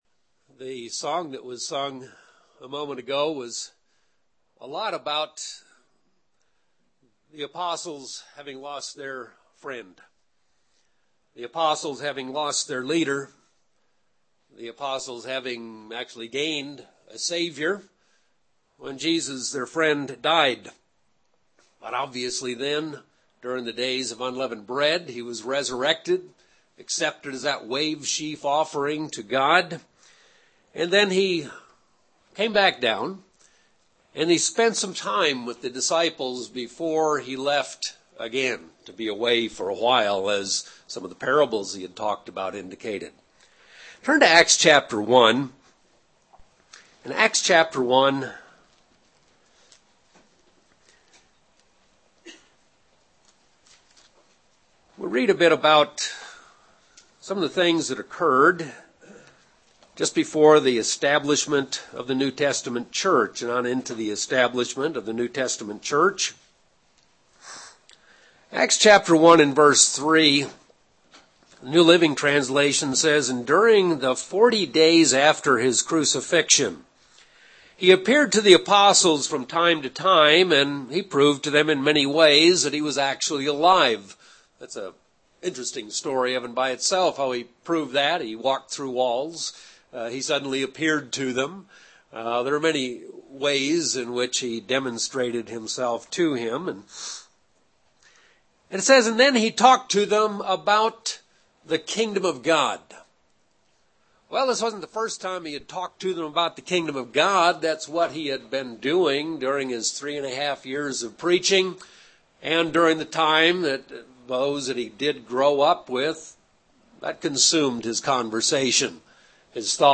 Sermons
Given in Albuquerque, NM Phoenix East, AZ